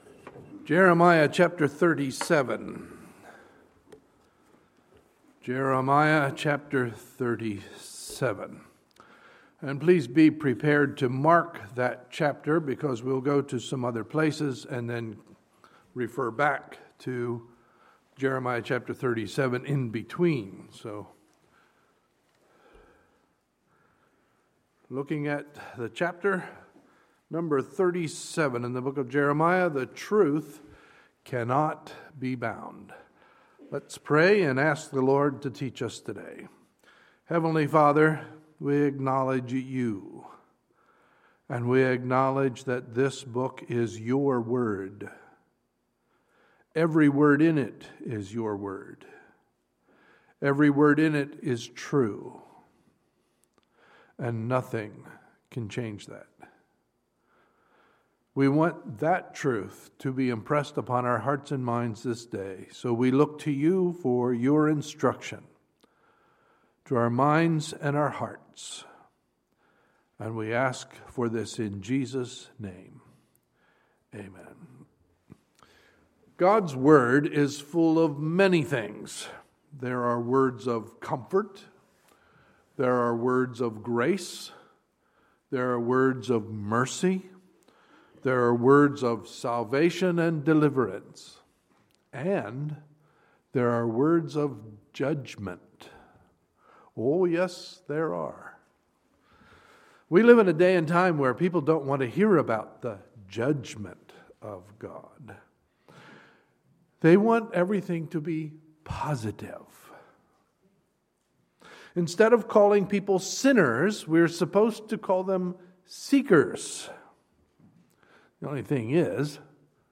Sunday, October 4, 2015 – Sunday Morning Service